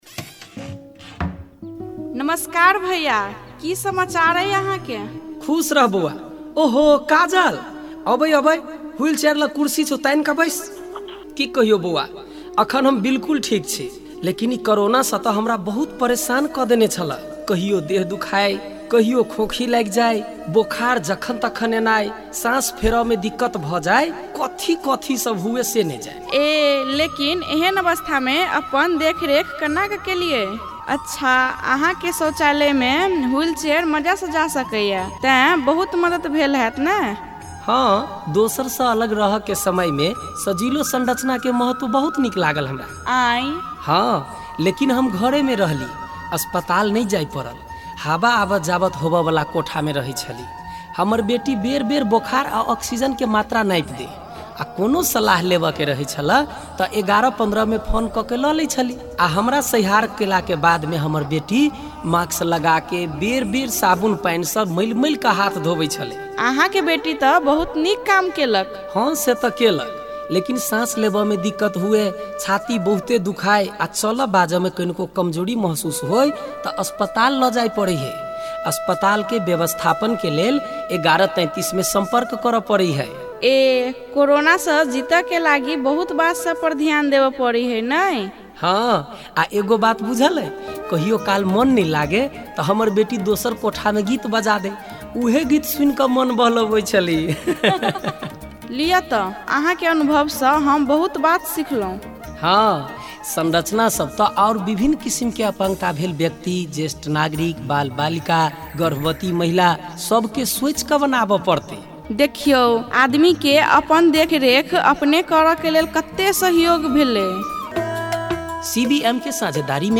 कोविड-१९ महामारीको बेलामा आफ्नो हेरचाह कसरी गर्ने भन्ने बारे सचेतनात्मक रेडियो सन्देश!